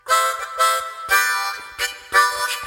简单的弯曲音符
描述：简单的适合更多的曲子，用G口琴演奏，可能最适合D或Dmin、G或Gmin及其相对小调的歌曲。在秘鲁利马这里我的小浴室里，用变焦H2录制的。
标签： 90 bpm Acoustic Loops Harmonica Loops 880.64 KB wav Key : Unknown